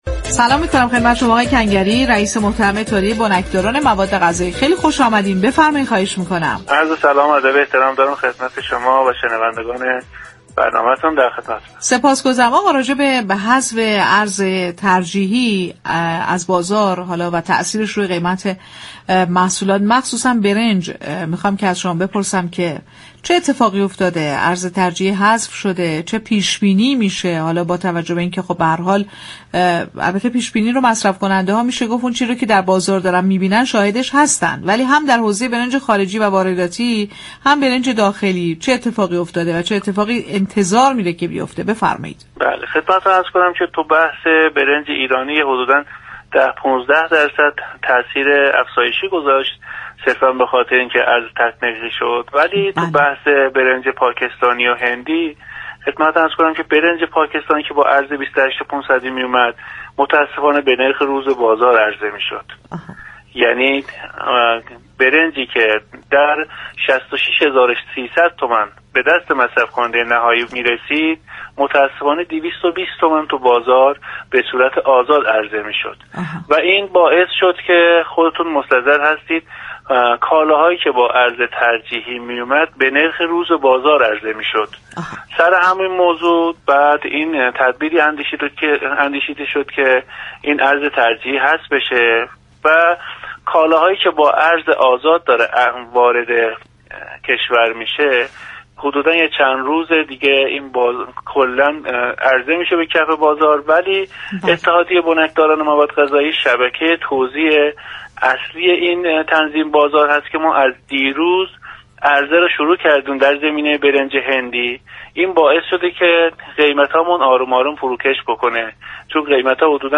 برنامه«بازار تهران»، روزهای شنبه تا چهارشنبه 11 تا 11:55 از رادیو تهران پخش می‌شود.